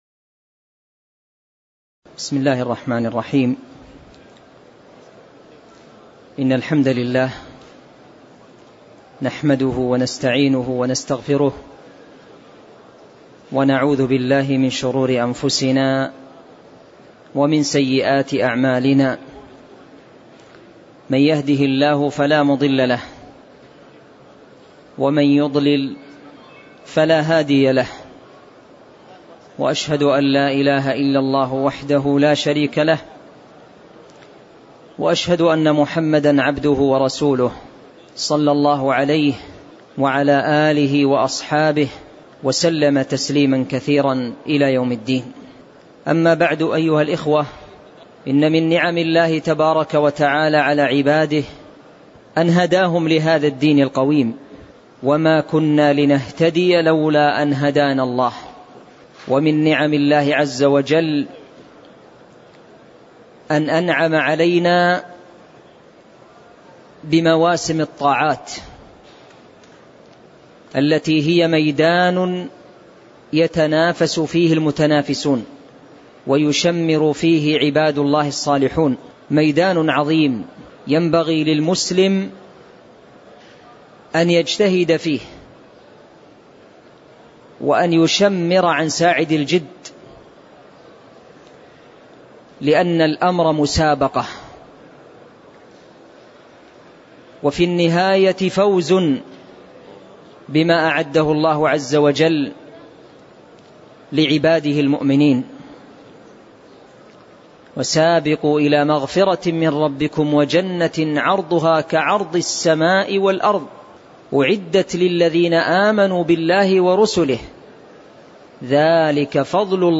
تاريخ النشر ١٤ رمضان ١٤٤٠ هـ المكان: المسجد النبوي الشيخ